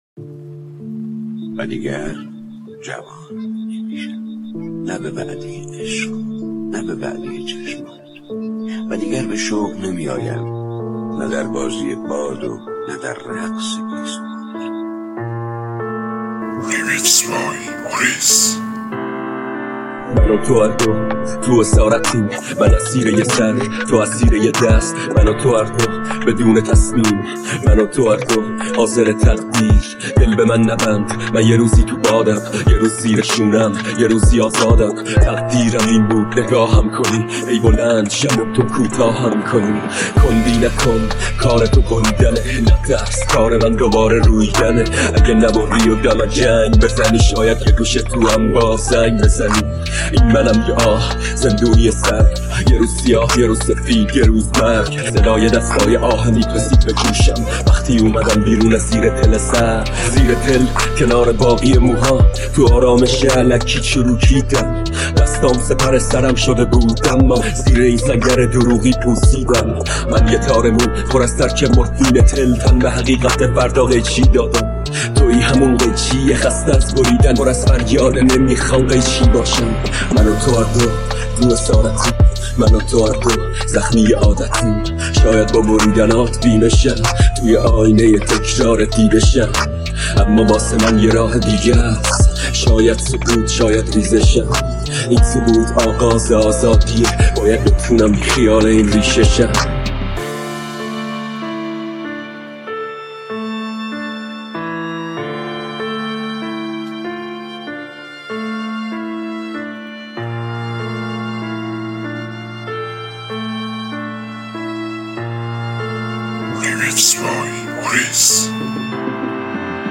ریمیکس جدید غمگین اهنگ رپ